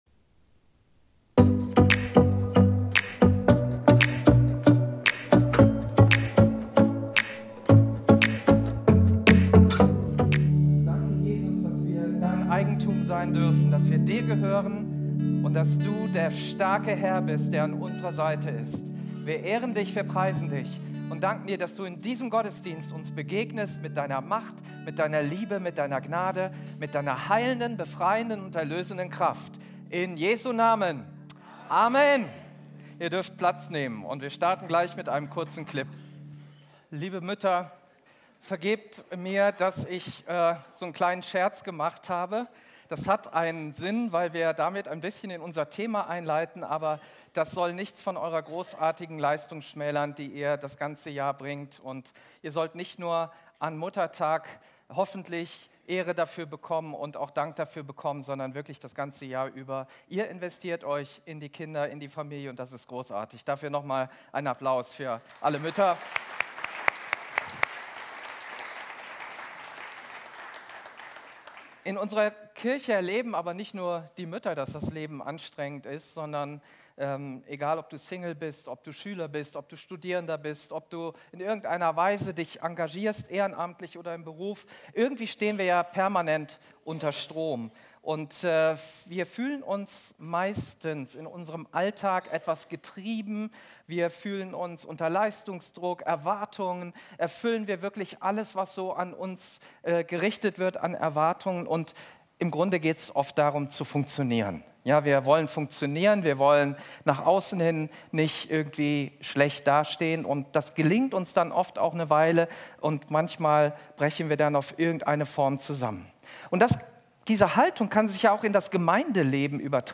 Live-Gottesdienst aus der Life Kirche Langenfeld.
Sonntaggottesdienst